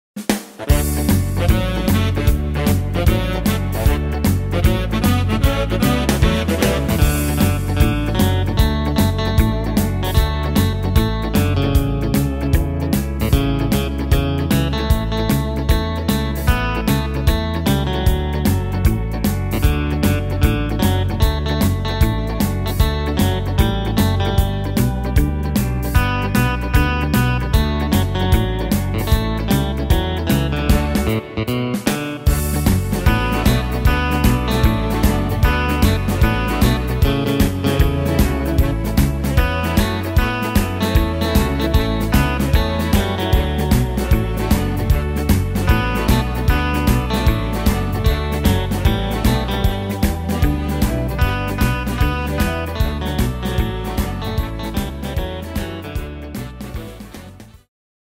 Tempo: 152 / Tonart: D-Dur